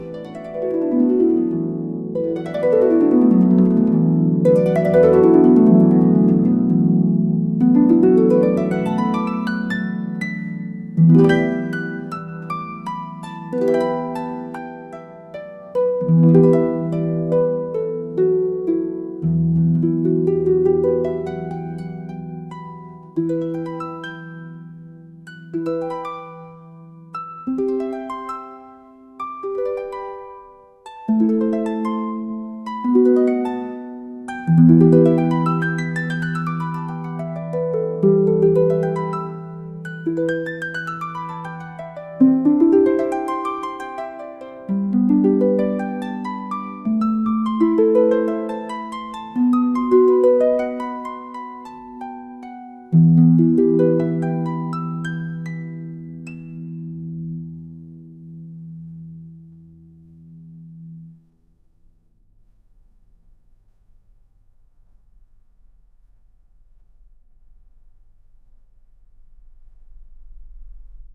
Lulaby Music